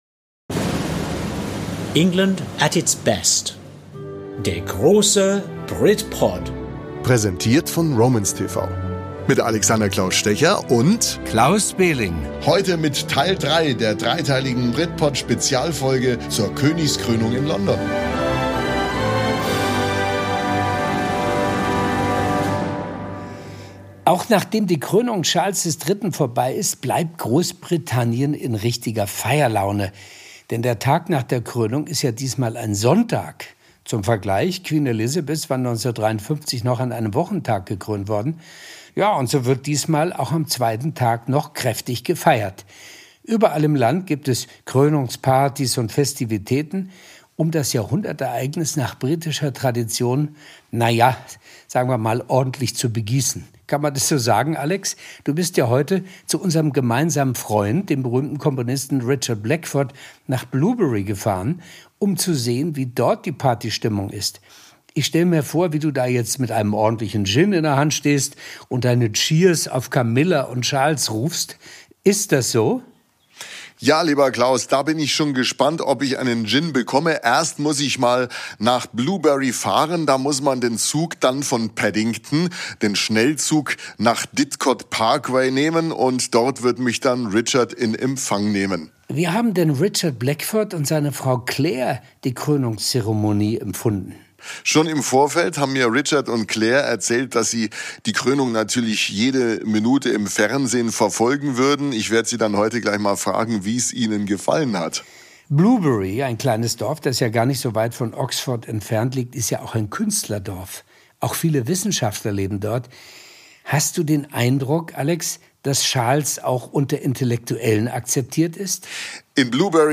In der historischen Dorfkirche stimmt der Kirchenchor die schönsten Lieder an und es gibt einen speziellen Segen für King Charles III.